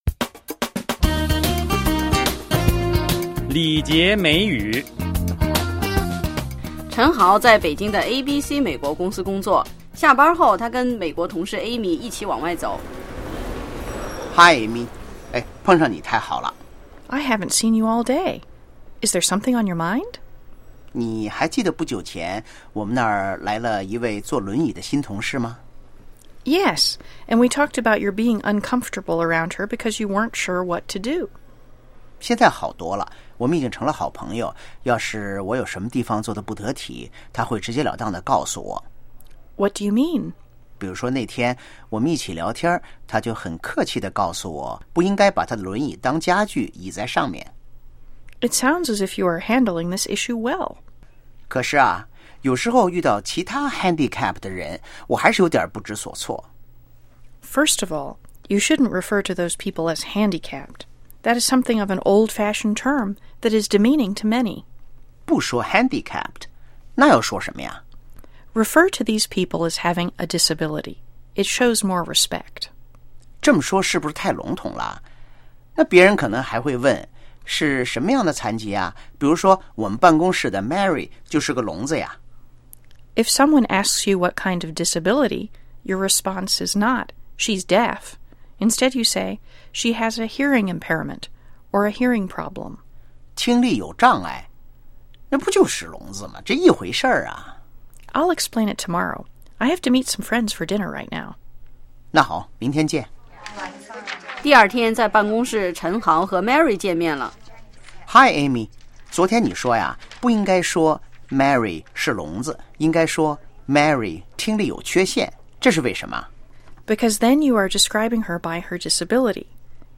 (Office ambience)